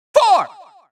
countIn4Farther.wav